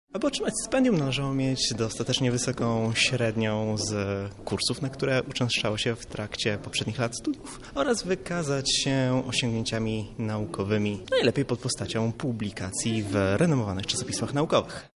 O wymaganiach, które należało spełnić aby zostać laureatem mówi